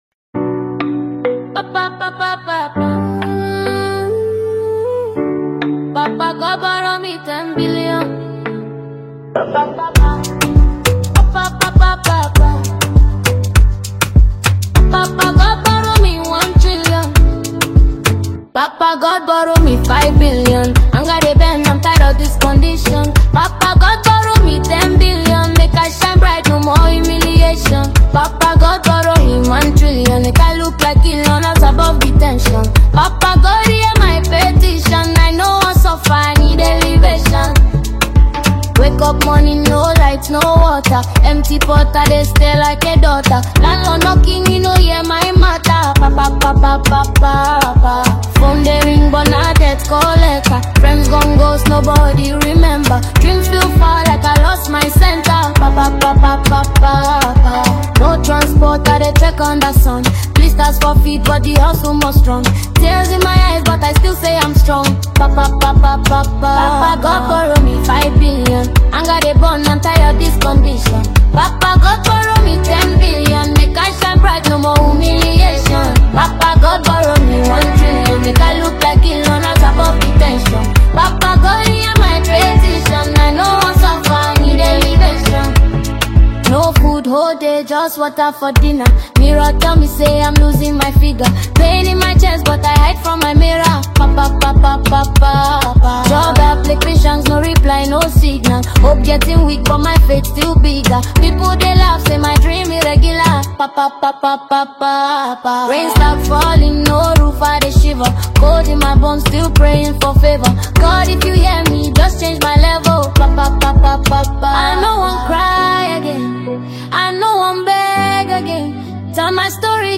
• Upbeat and danceable rhythms
• Catchy melodic patterns that stick after one listen
• Smooth and balanced instrumental layering
• Clarity and ease of understanding
• Emotional undertone that adds authenticity
• Conversational and relatable tone
• Its catchy and memorable hook
• Its easy, sing-along vibe
• Afrobeat and street-inspired sounds